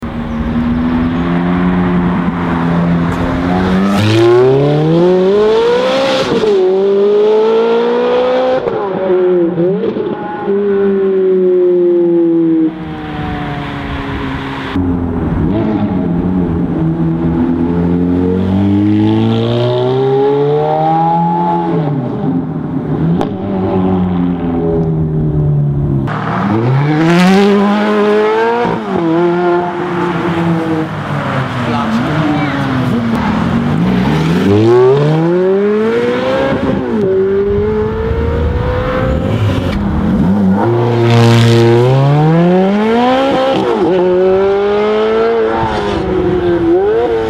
ENGINE SIZE 4.3 L V8